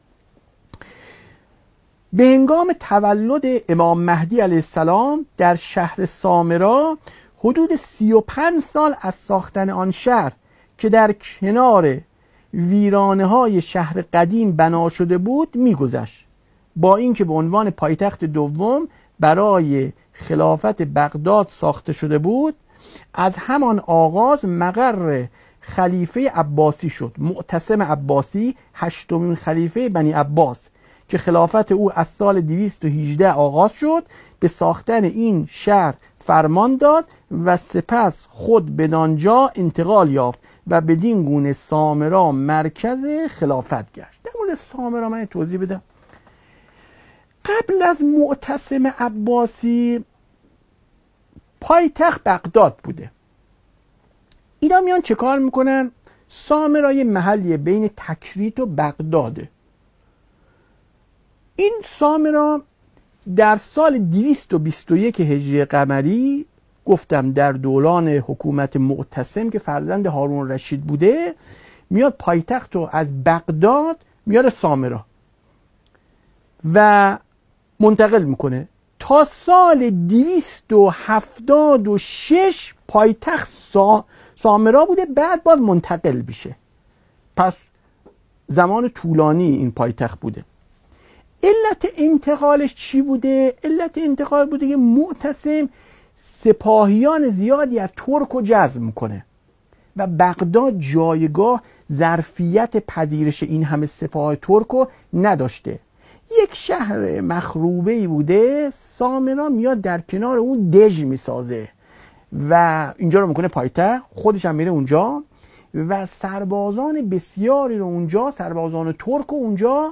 صوت ســـخنرانی: